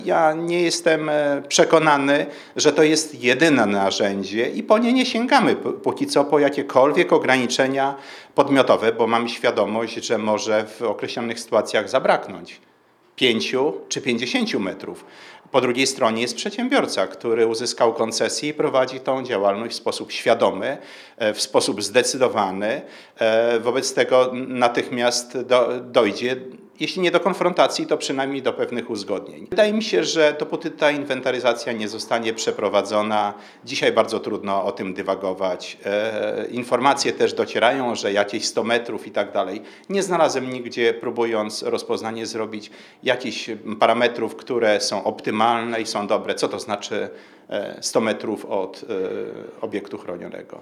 Zdzisław-Przełomiec-przewodniczący-Rady-Miasta-Suwałki-02-1.mp3